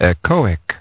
Транскрипция и произношение слова "echoic" в британском и американском вариантах.